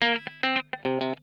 PICKIN 5.wav